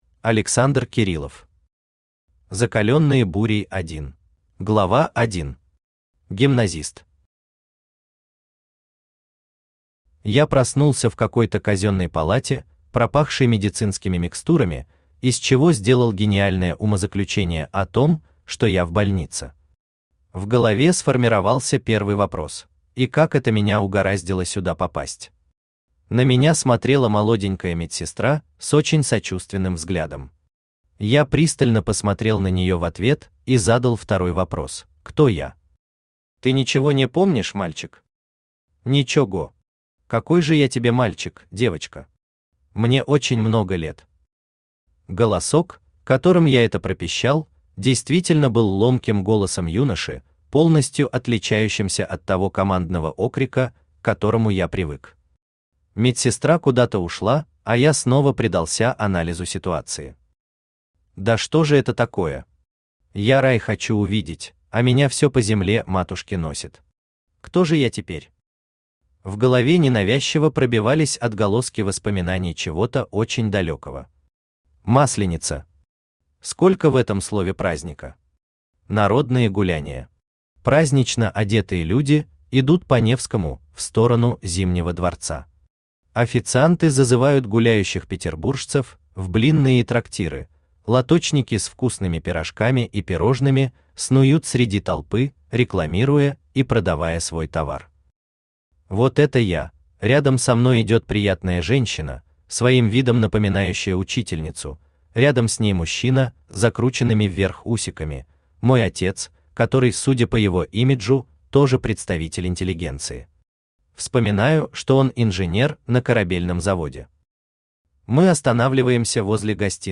Aудиокнига Закаленные бурей 1 Автор Александр Леонидович Кириллов Читает аудиокнигу Авточтец ЛитРес.